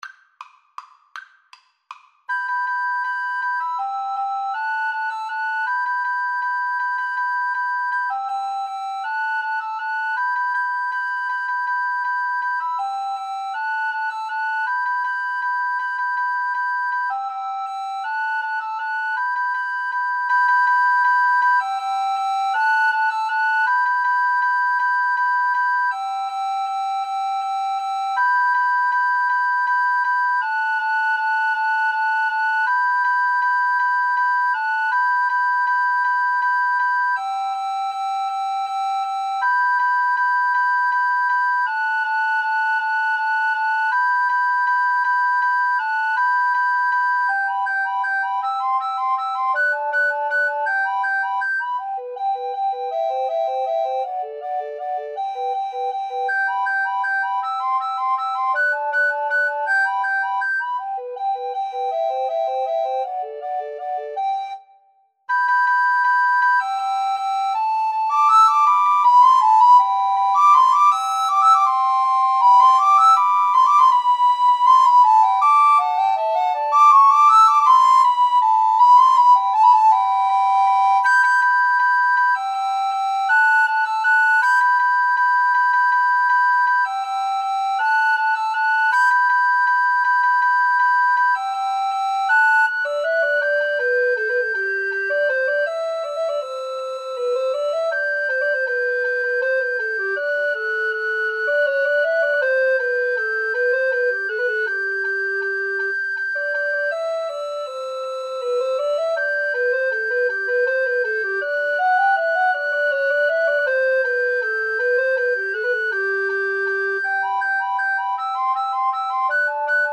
Allegro Vivo = 160 (View more music marked Allegro)
Recorder Trio  (View more Intermediate Recorder Trio Music)
Classical (View more Classical Recorder Trio Music)